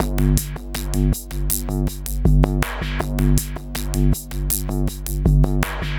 Index of /90_sSampleCDs/Spectrasonic Distorted Reality 2/Partition A/03 80-89 BPM